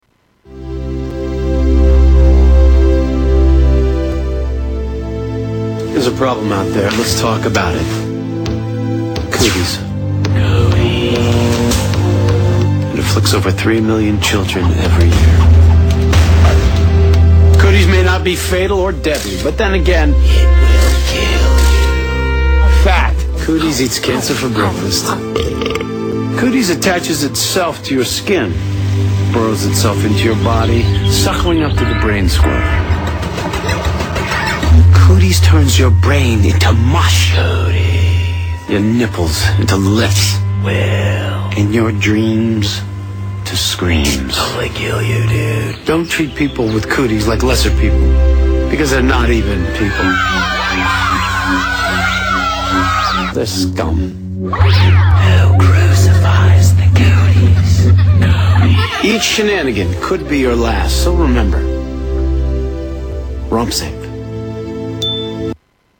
Tags: Media Cooties Cooties Commercials Public Service Announcement Cooties Awareness